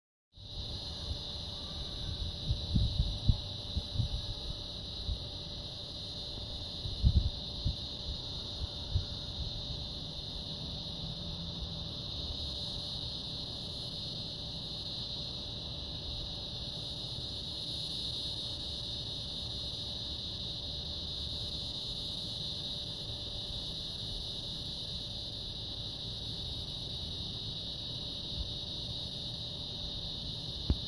蝉2
描述：八月中旬弗吉尼亚州郊区的白天时间响起。
Tag: 性质 现场记录 夏天 昆虫